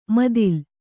♪ madil